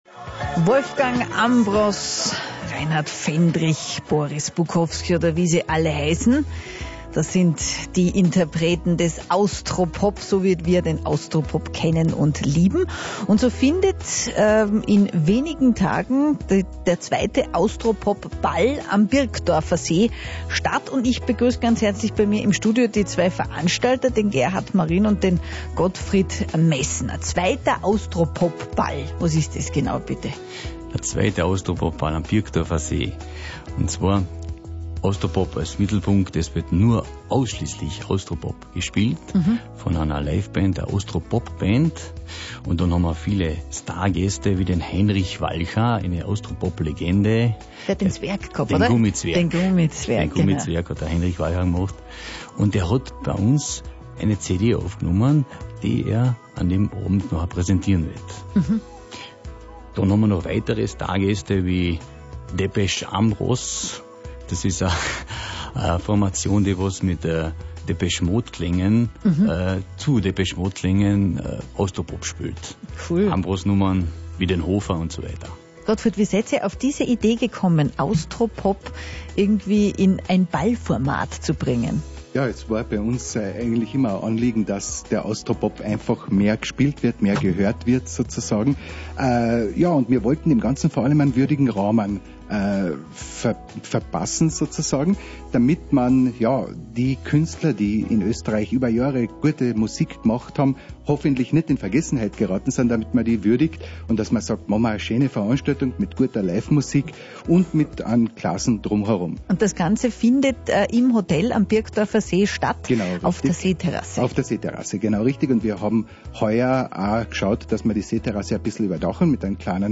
Interview Radio Kärnten Austro-Pop-Ball 2011